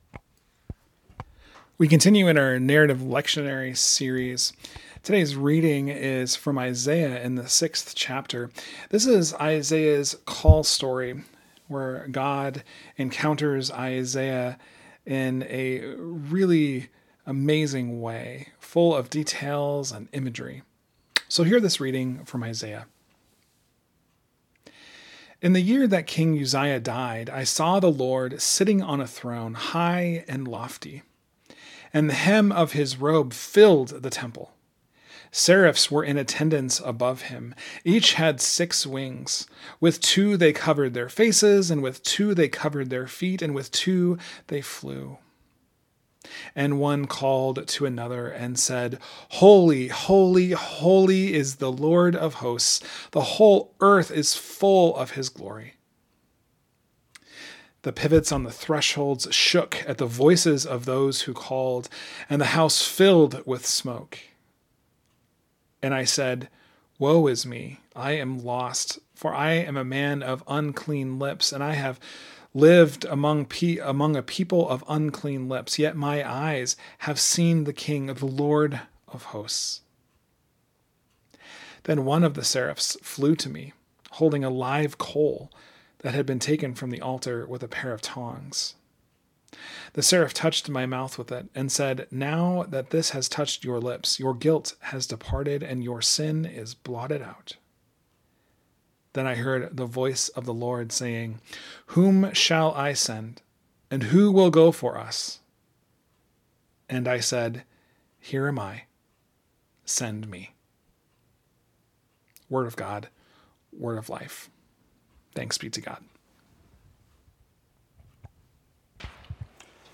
Sermons | Joy Lutheran Church